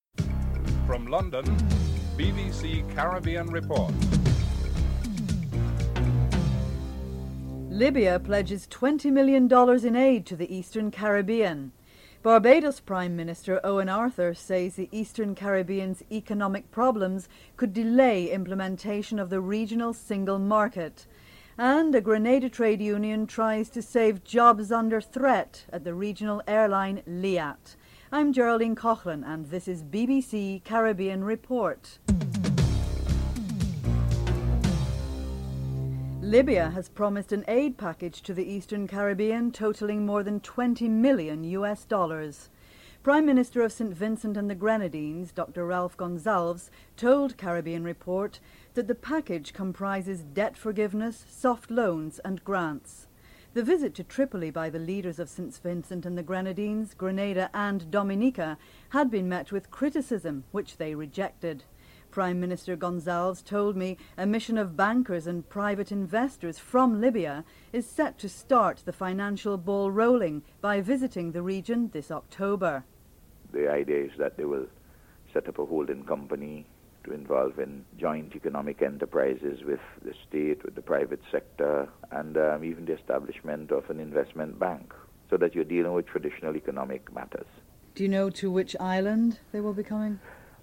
2. Libya pledges twenty million in aid to the Eastern Caribbean. Dr Ralph Gonsalves is interviewed (00:35-03:49)